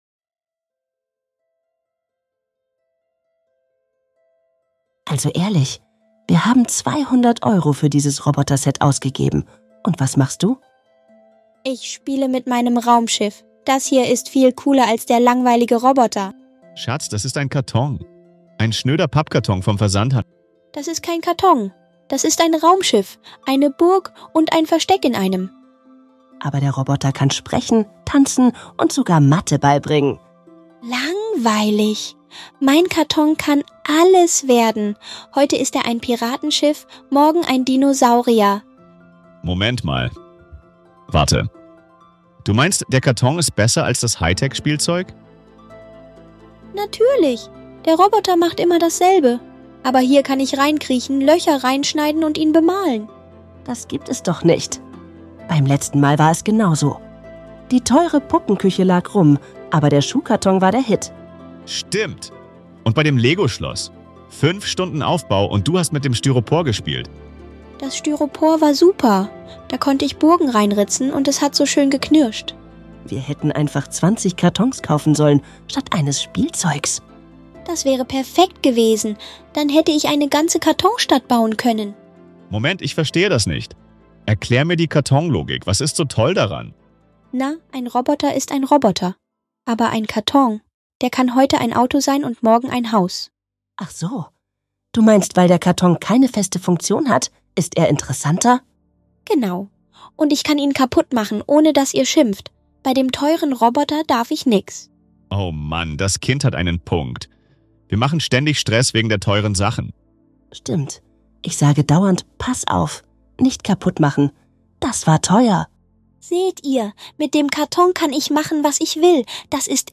In diesem lustigen Gespräch